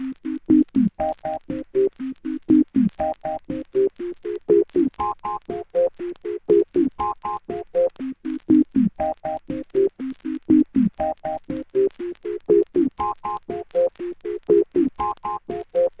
Jumping Lofi Bells Lofi Trip Hop Bells Sampl 120BPM
描述：我想我擅长制作带有黑胶效果的Lofi Bells和Love Filter :D .我把它放在Trip Hop里，因为这个流派需要更多的Loop。
Tag: 120 bpm Trip Hop Loops Percussion Loops 2.69 MB wav Key : Unknown